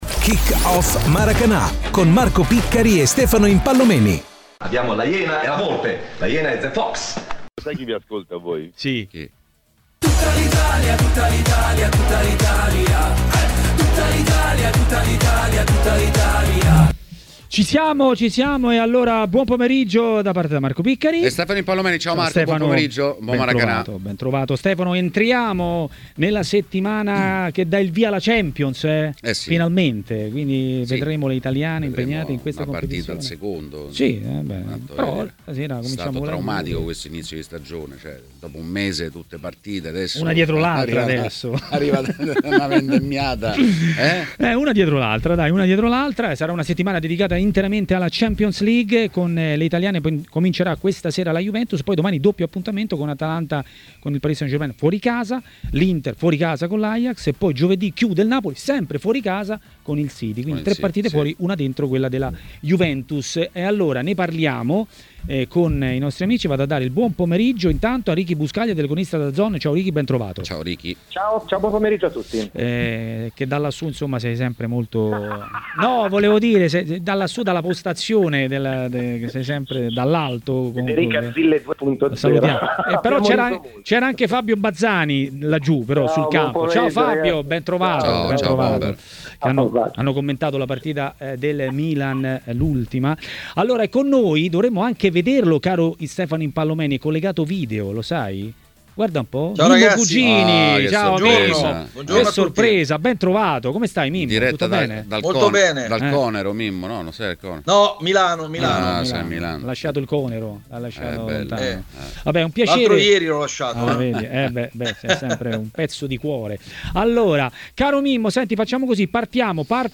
L'ex attaccante Fabio Bazzani è intervenuto a Maracanà, trasmissione di TMW Radio.